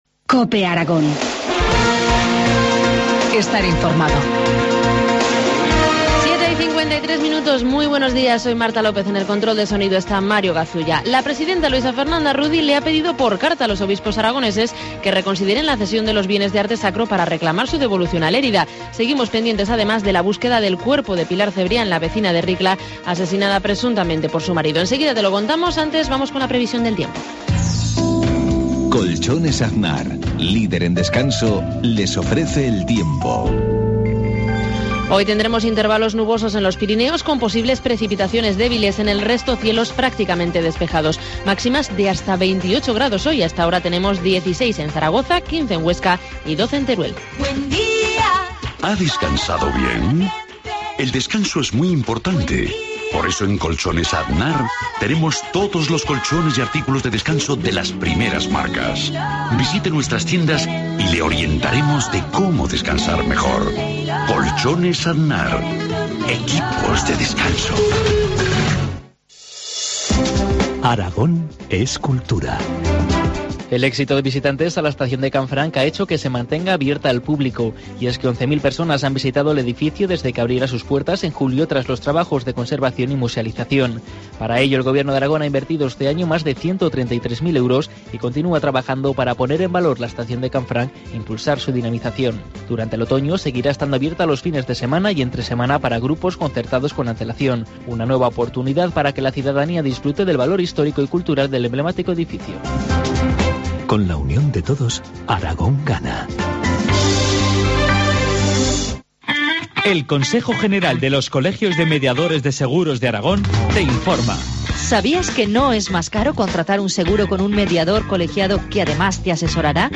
Informativo matinal, miércoles 16 de octubre, 7.53 horas